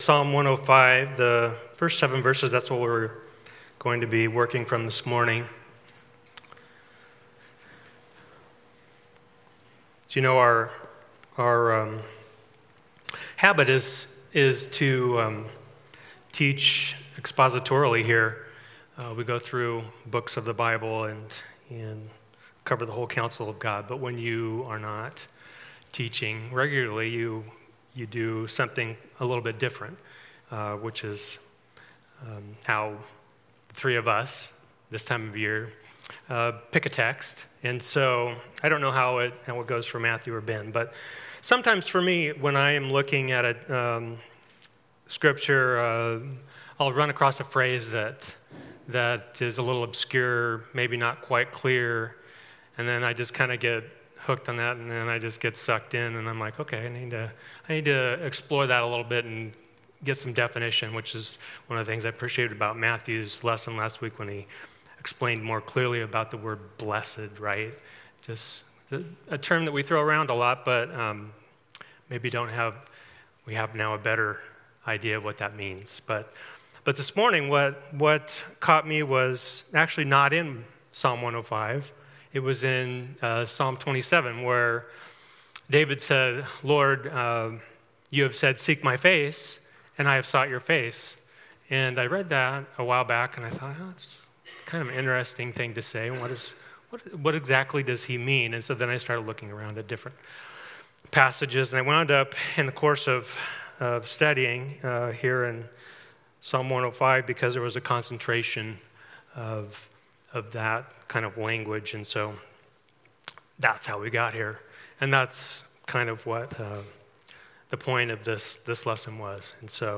Psalm 105:1-7 Service Type: Morning Worship Service « Lesson 4